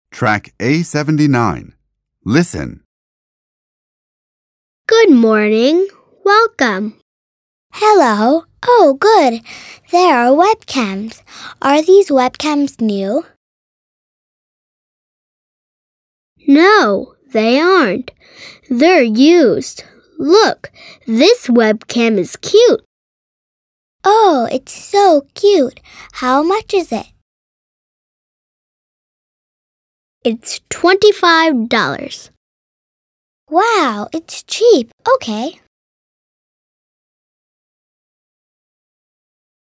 این مجموعه با لهجه ی  امریکن و توسط نویسندگانی چون Beat Eisele, Catherine Yang Eisele, Stephen M. Hanlon, Rebecca York Hanlon به رشته تحریر درآمده است و همچنین دارای سطح بندی از مبتدی تا پیشرفته می باشد و سراسر مملو از داستان های جذاب با کاراکتر های دوست داشتنی می باشد که یادگیری و آموزش زبان انگلیسی را برای کودکان ساده و لذت بخش تر خواهد کرد.